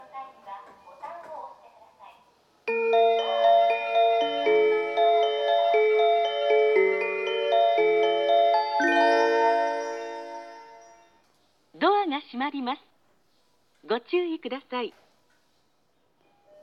接近放送
発車メロディー(朝の静けさ)   こちらは女声Verです。